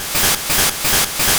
Attack sound effects are now in the folder of the type of their attack Pokémon flicker briefly when hit 2021-10-22 12:21:12 +02:00 240 KiB Raw Permalink History
Fly_Hit.wav